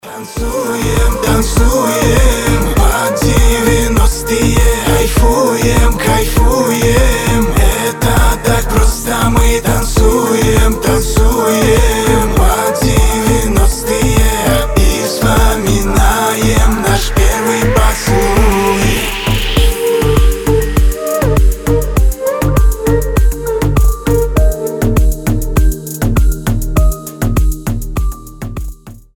поп
ностальгия